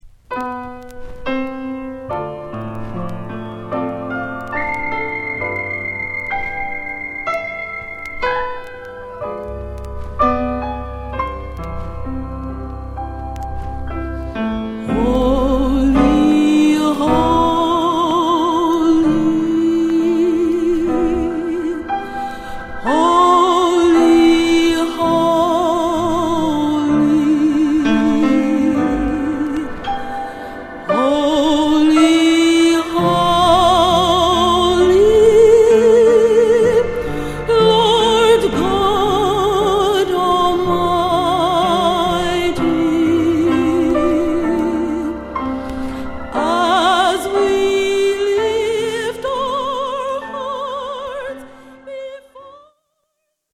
又、所々オルガンやヴォーカル部分等が抜けるので、クリエイターの方にもおすすめです！